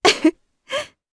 Hilda-Vox_Happy2_jp.wav